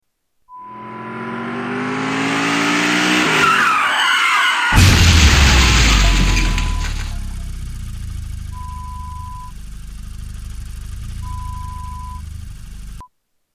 Car Crash
Category: Sound FX   Right: Personal